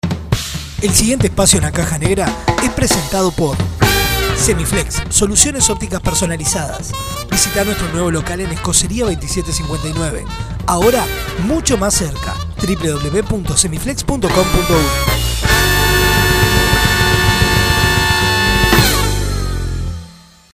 PRESENTACION-DE-ESPACIO.mp3